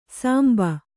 ♪ sāmba